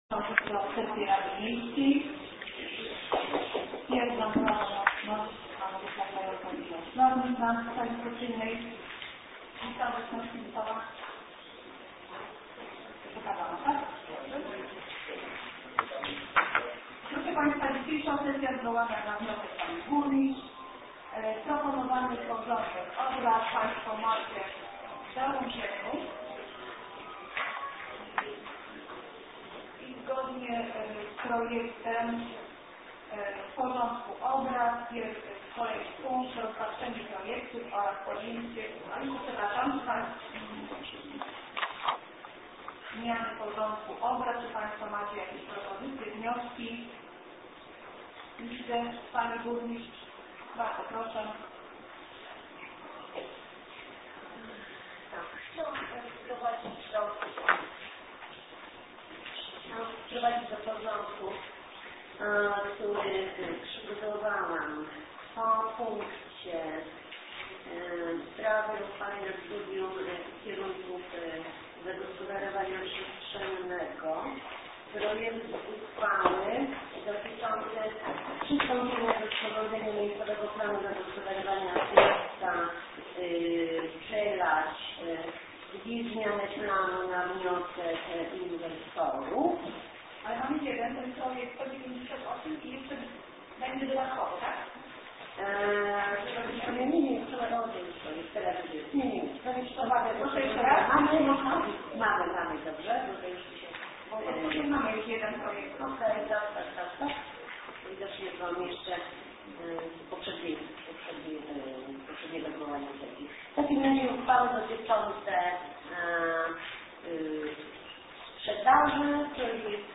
Plik dźwiękowy z Sesji Rady Miejskiej nr LVI z dnia 19.08.2013r.